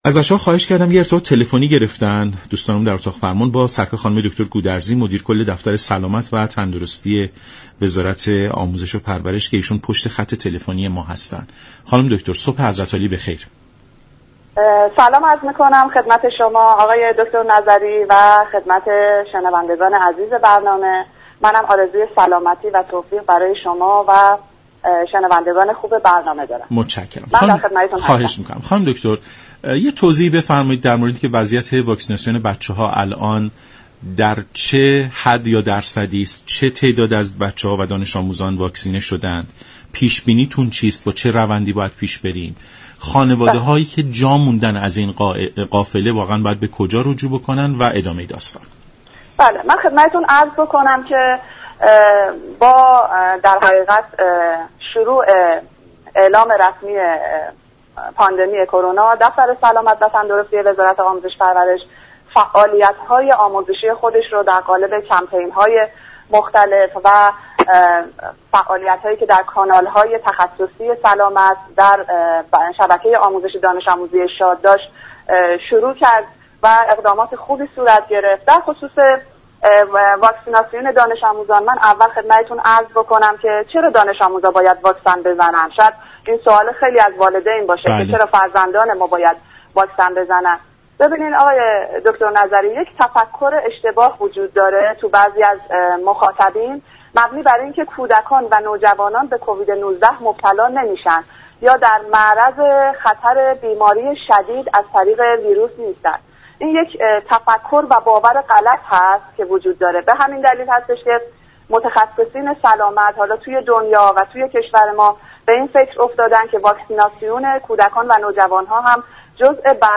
به گزارش شبكه رادیویی ایران، اعظم گودرزی مدیر كل دفتر سلامت و تندرستی وزارت آموزش و پرورش در برنامه «سلام صبح بخیر» به اهمیت واكسیناسیون دانش آموزان پرداخت و گفت: اینكه برخی ها فكر می كنند كودكان و نوجوانان به بیماری كرونا مبتلا نمی شوند باور و تفكری اشتباه است، برای كاهش زنجیره انتقال این بیماری متخصصان سلامت، واكسیناسیون كودكان و نوجوانان را جزو برنامه های خود قرار داده اند.